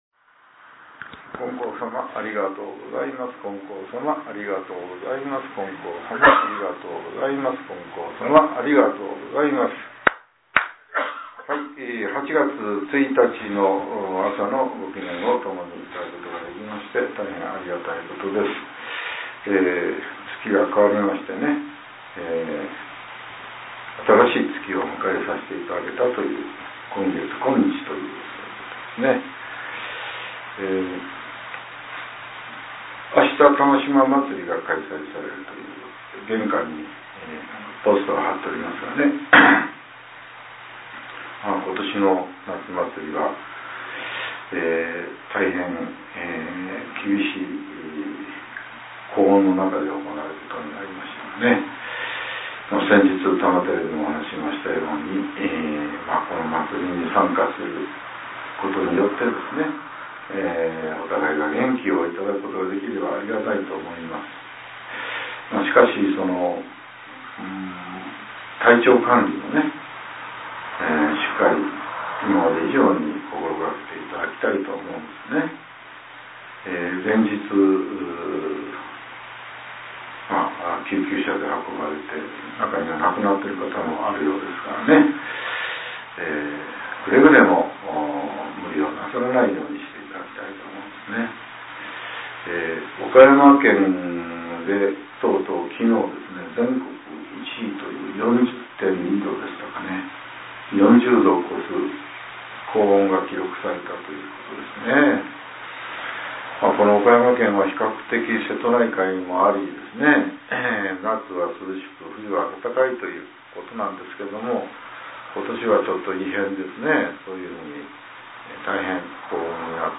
令和７年８月１日（朝）のお話が、音声ブログとして更新させれています。